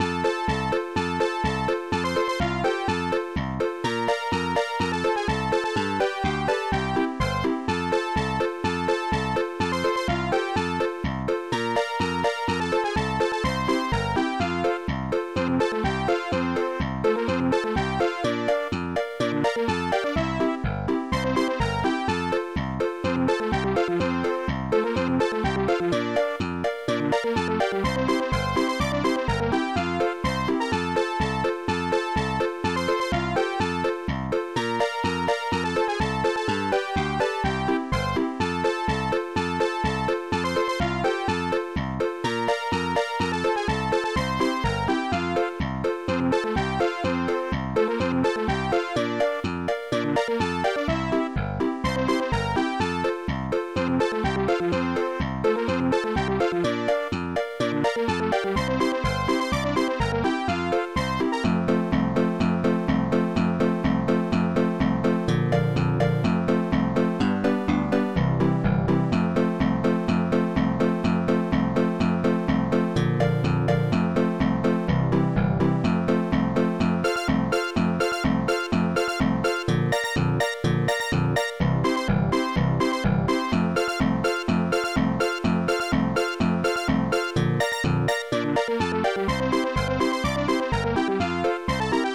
Tracker AMOS Music Bank Tracks 4 Samples 4 Patterns 8 Instruments Not named Not named Not named Not named